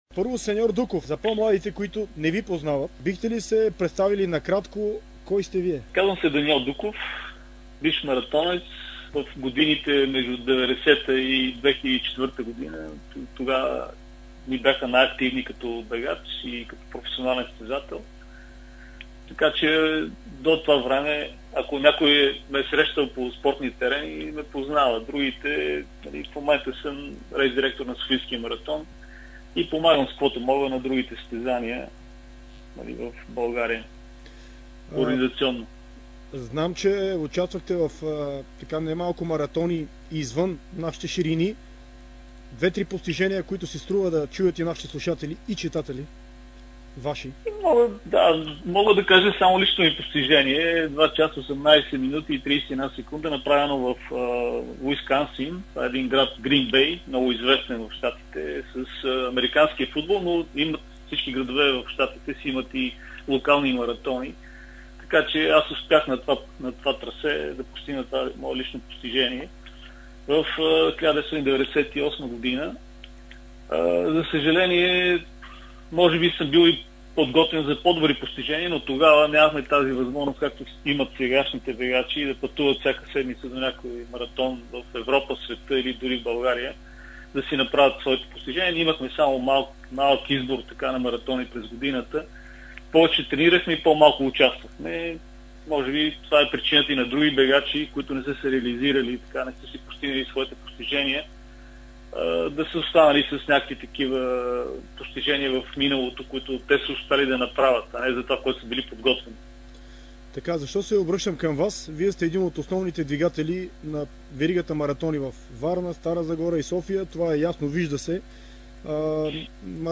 В интервю за Дарик радио и dsport